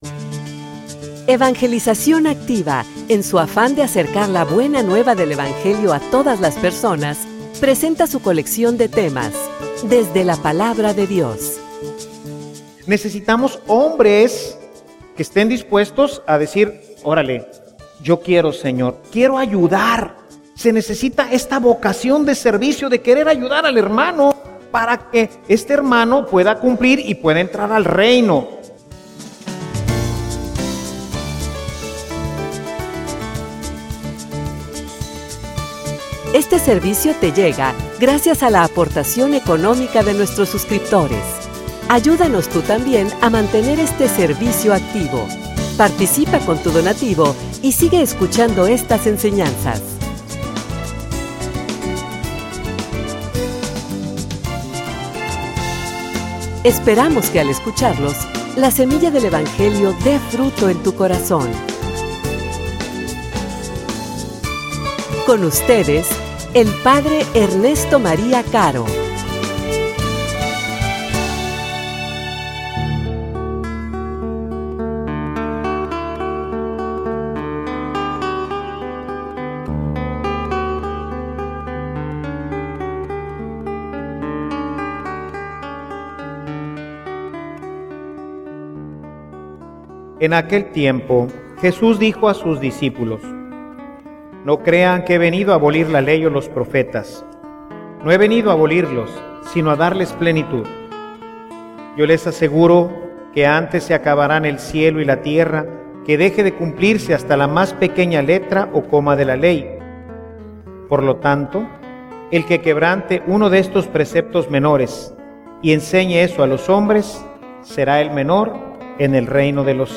homilia_Sin_sacerdotes_no_hay_futuro.mp3